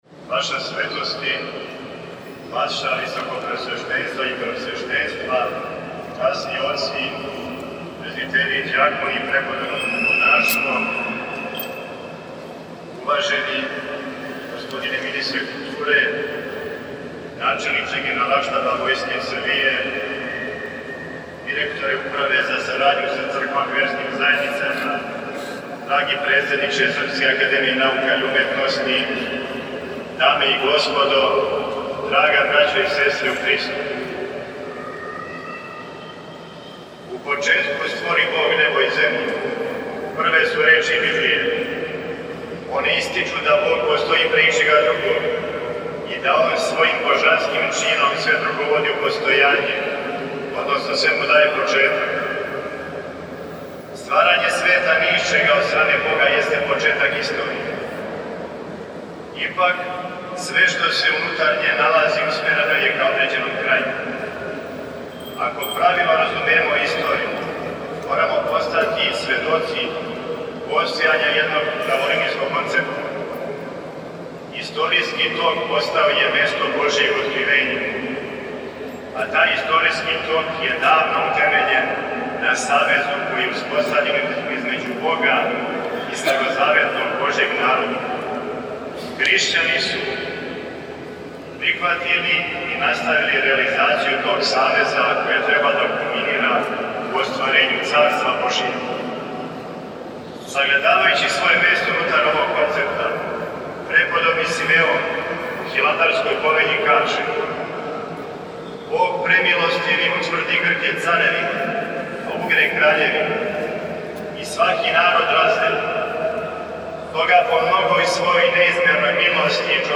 Његова Светост Патријарх српски г. Порфирије је началствовао 8. септембра 2024. године у храму Светог Саве у Београду светом архијерејском Литургијом и свечаним чином хиротоније изабраног и нареченог високодостојног архимандрита Тихона за Епископа моравичког, викара Патријарха српског.
Послушајте у целости звучни запис приступне беседе Његовог Преосвештенства Епископа моравичког г. др Тихона (Ракићевића), викара патријарха српског: